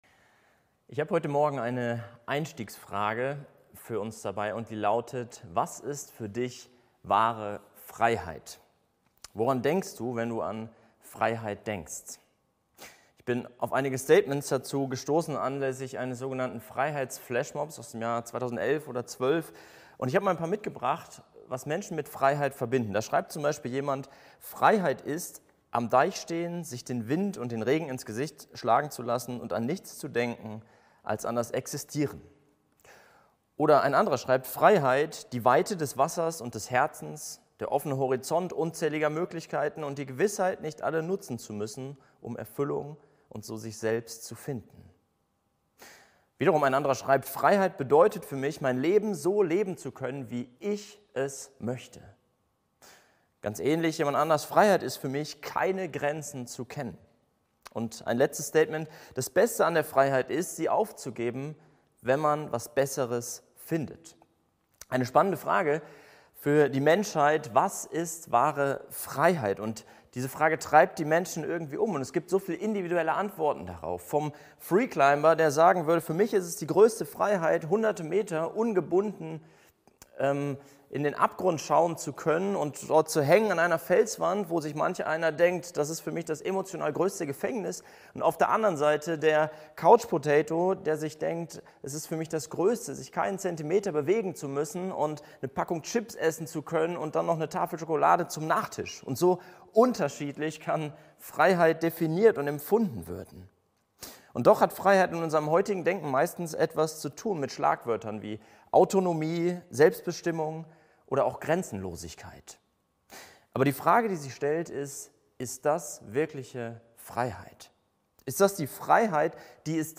Die Gliederung der Predigt ist: Befreit zu… 1. neuer Entschlossenheit (V.15-17) 2. neuer Hingabe (V.18-19) 3. neuer Frucht (V.20-23) HausKreisLeitfaden Aufnahme (MP3) 33 MB PDF 193 kB Zurück Wer ist König?